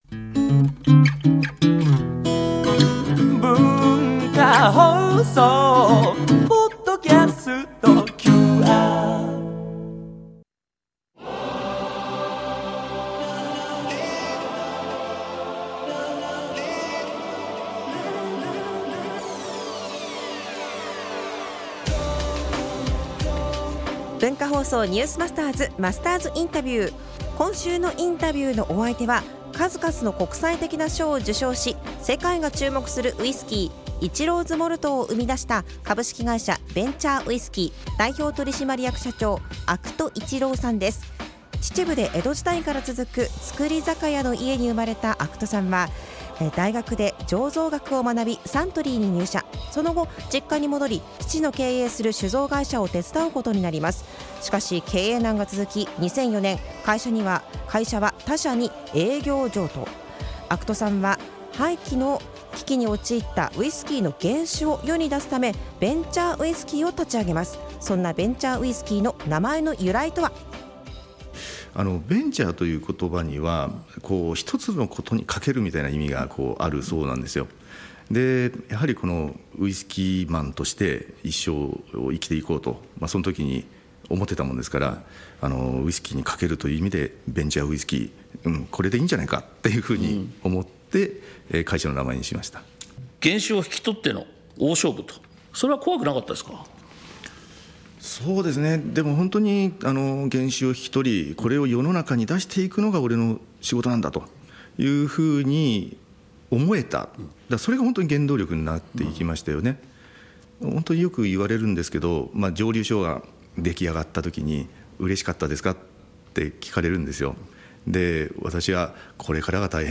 （月）～（金）AM7：00～9：00　文化放送にて生放送！